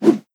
whoosh.wav